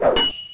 Sword_whoosh.wav